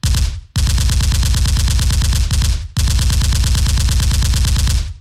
sfx MACHINE GUN